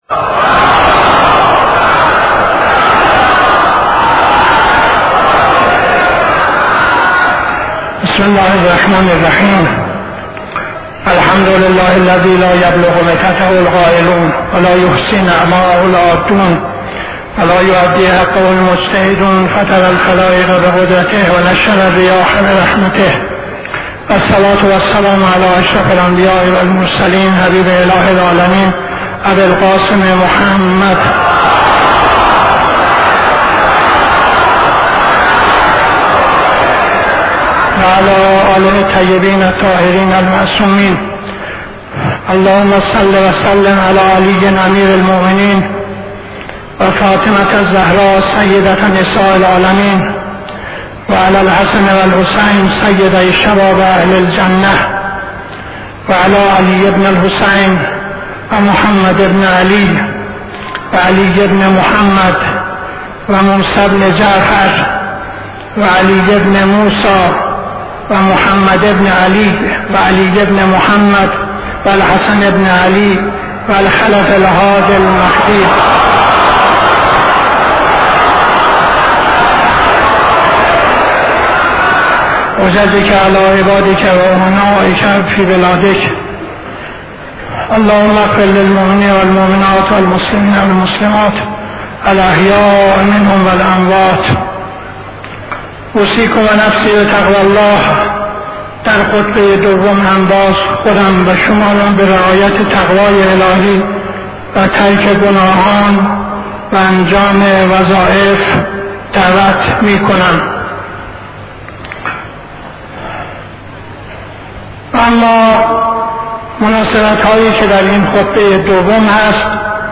خطبه دوم نماز جمعه 16-07-72